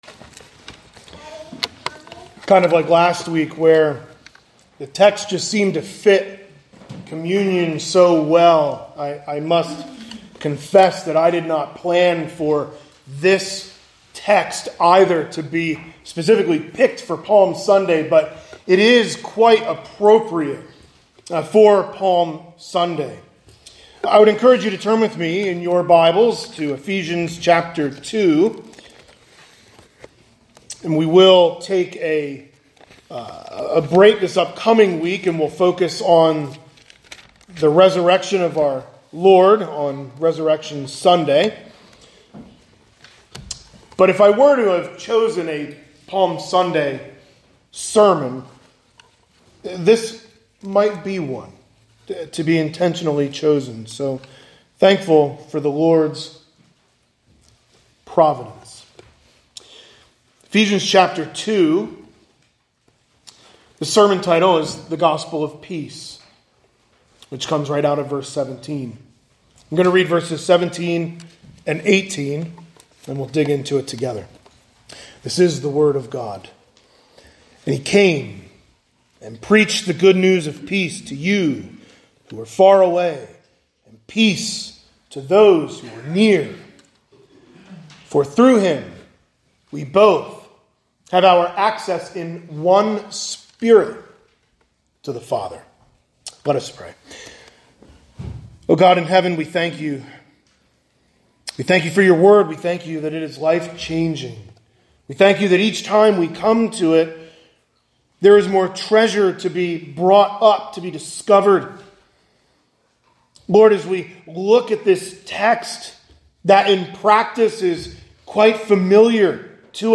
Sunday Morning Sermons | Zionsville Bible Fellowship Church